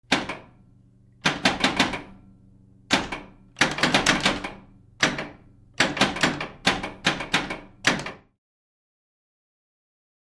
Pinball Machine; Flipper Movement